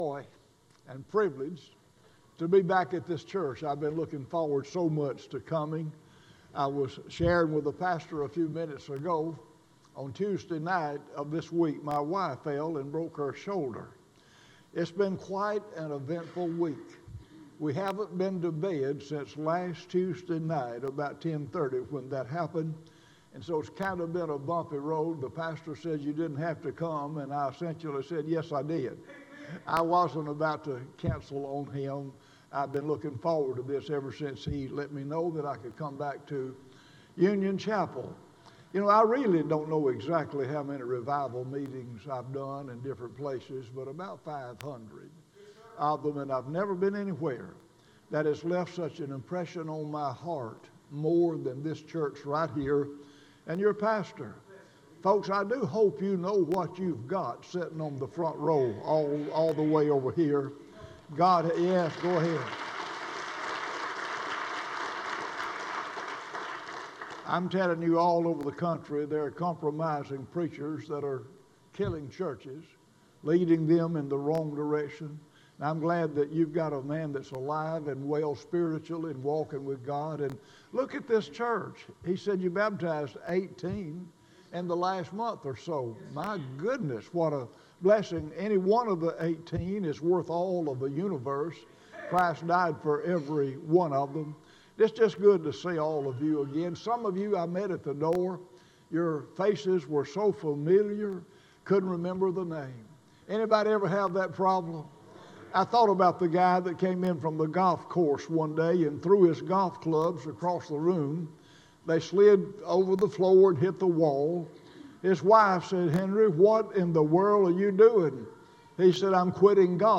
Sermons Archive - Union Chapel Baptist